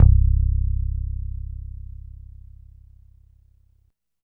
85 BASS C2.wav